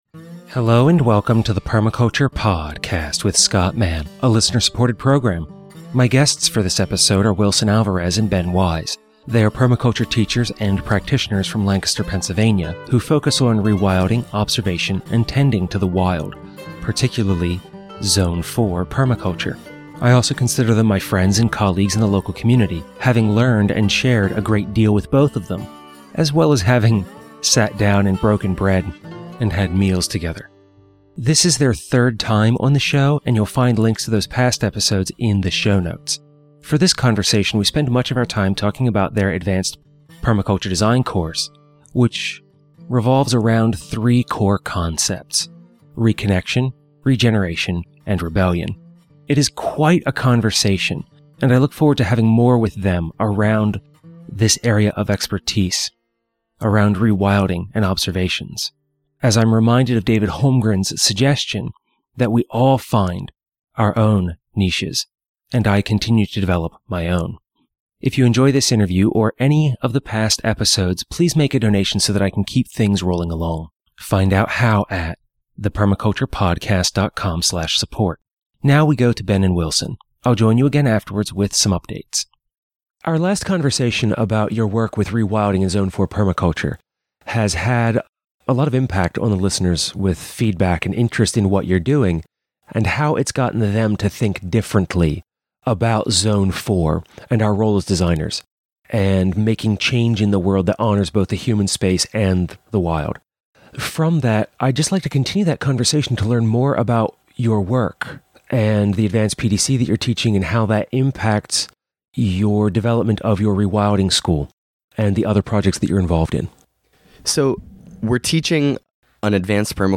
This episode is the second half of a two-part Susquehanna Permaculture Round Table discussion
includes several members of the live home audience joining in to share their thoughts and questions on the topics at hand. With a mixture of humor and honesty, we continue talking about how to become right with ourselves and others in order to find and build community, and the right livelihood.